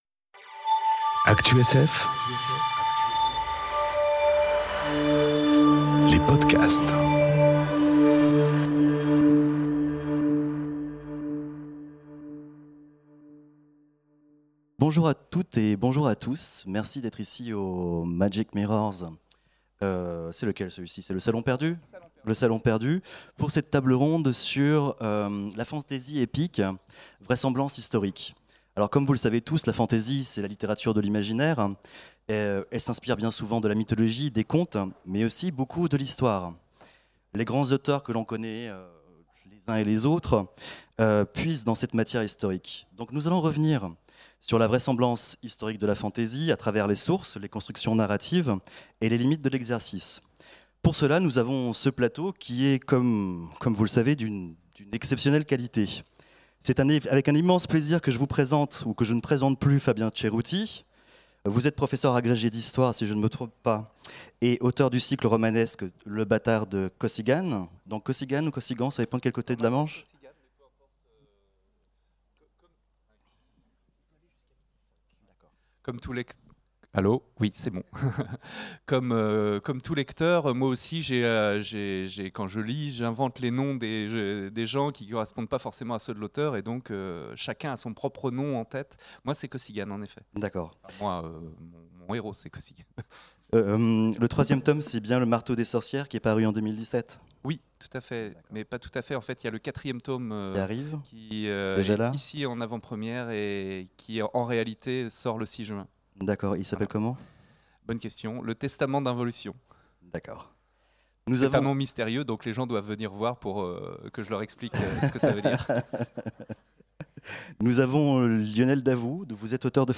Conférence Fantasy épique... Et vraisemblance historique enregistrée aux Imaginales 2018